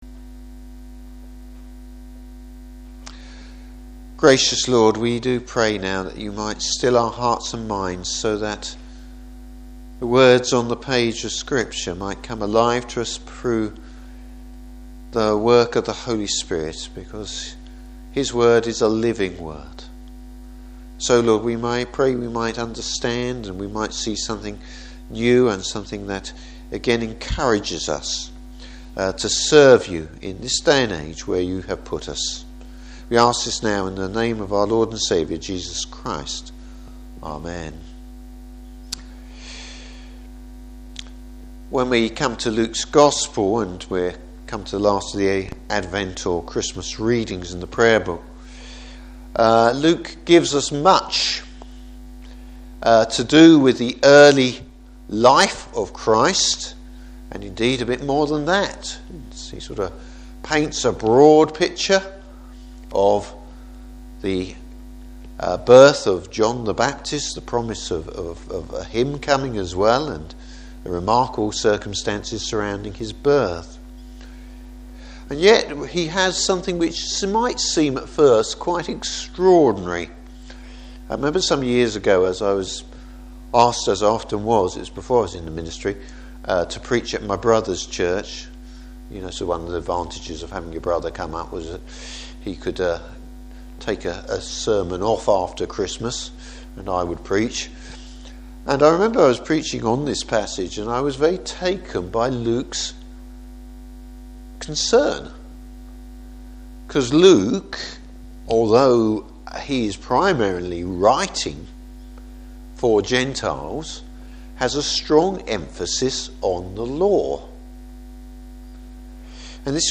Service Type: Evening Service How Jesus, even as an infant, fulfils the Law.